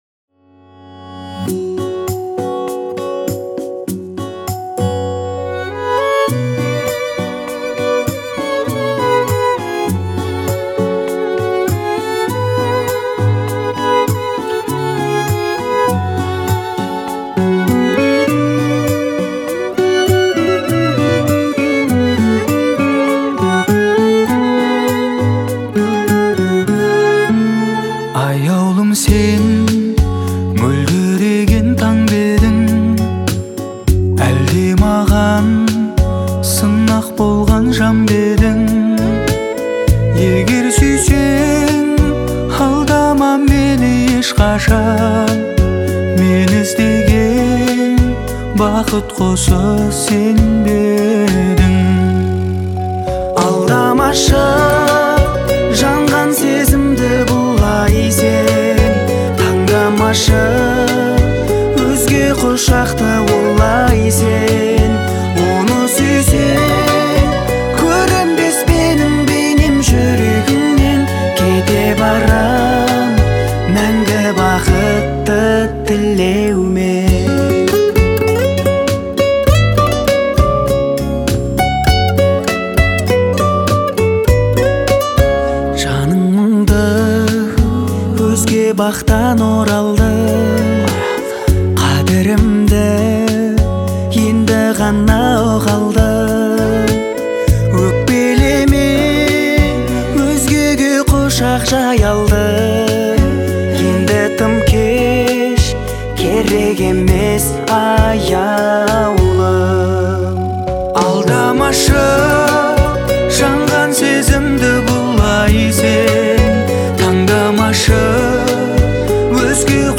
это трогательная композиция в жанре поп